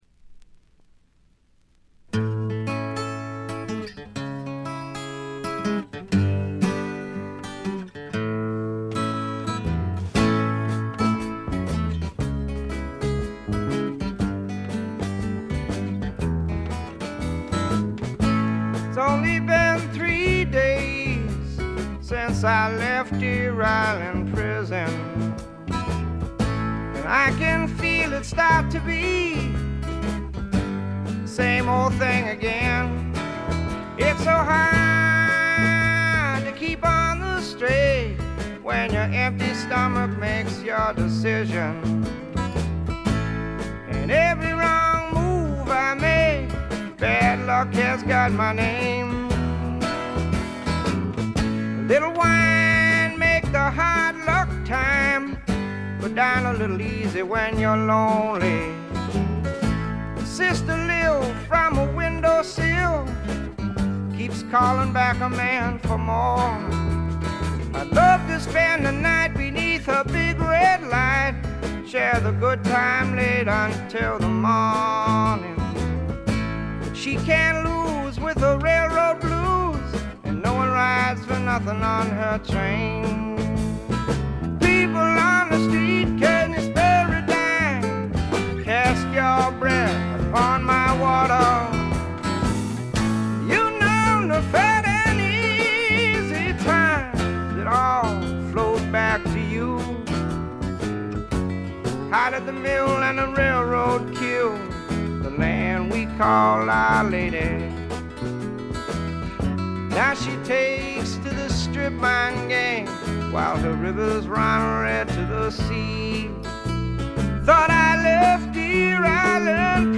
ほとんどノイズ感無し。
試聴曲は現品からの取り込み音源です。
Vocals, Guitar, Harmonica